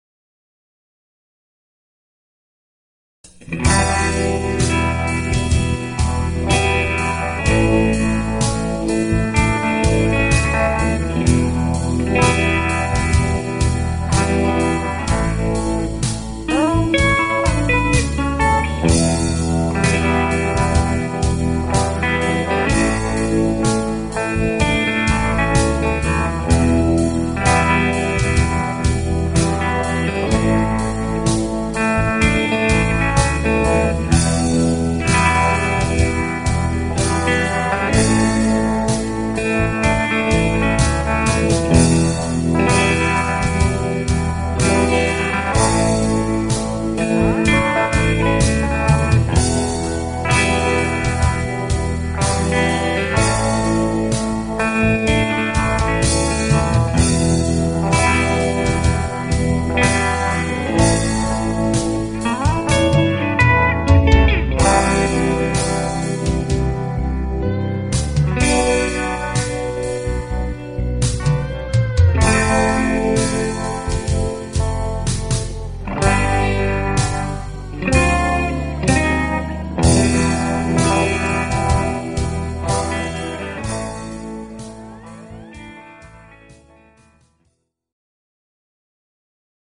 There is a 3 sec silent gap at the beginning of the clip.